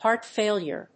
アクセントhéart fàilure